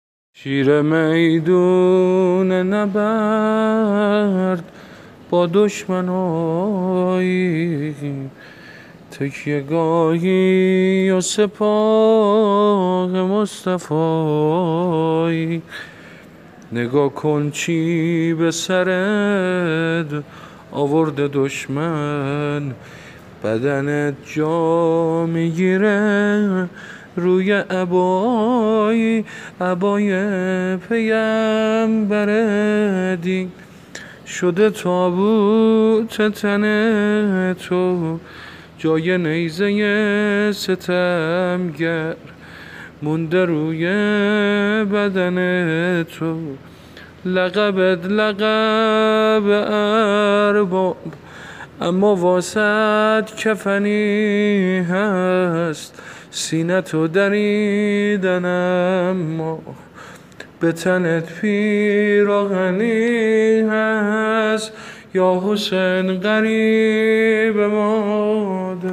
سبک زمزمه شهادت حضرت حمزه(ع) -(شیر میدون نبرد..)